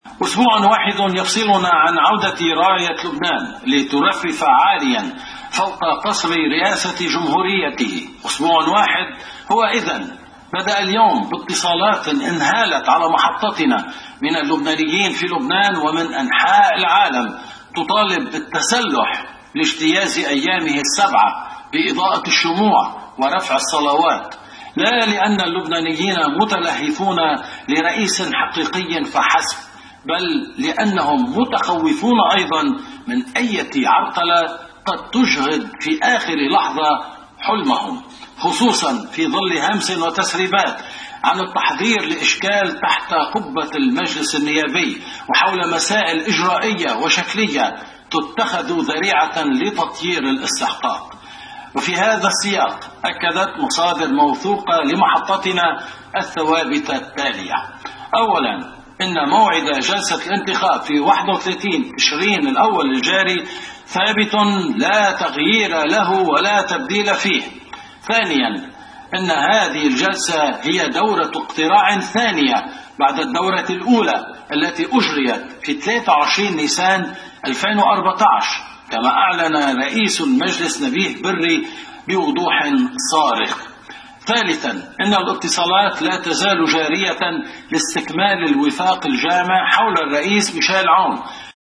مقتطفات من مقدمة نشرة اخبار الـ”OTV”: